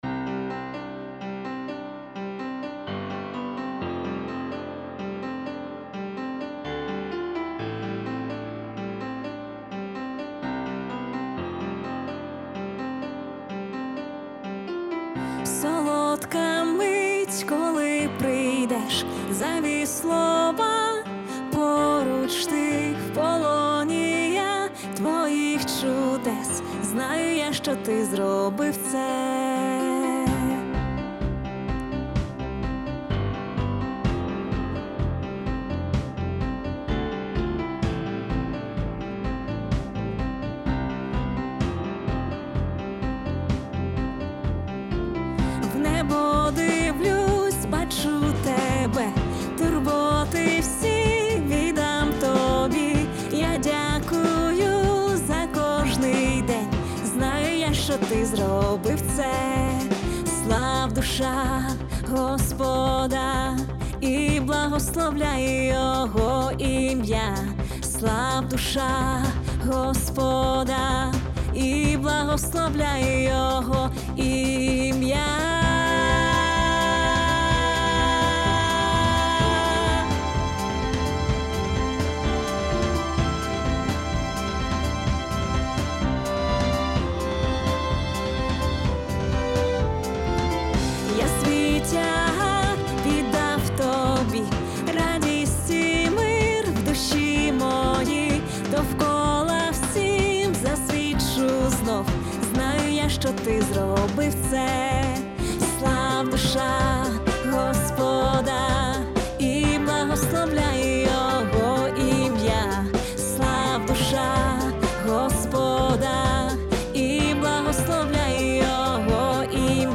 473 просмотра 362 прослушивания 8 скачиваний BPM: 127